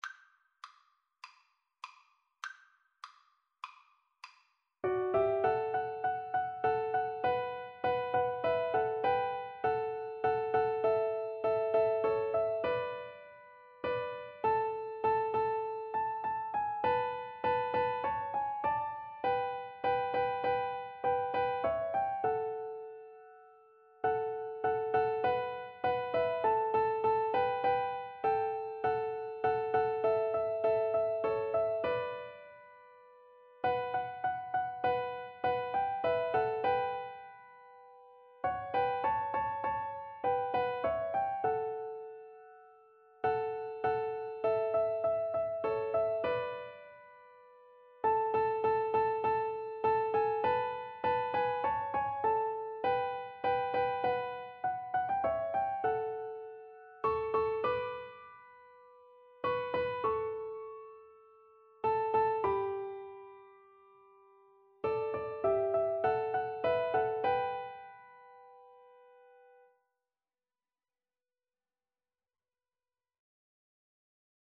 Free Sheet music for Piano Four Hands (Piano Duet)
4/4 (View more 4/4 Music)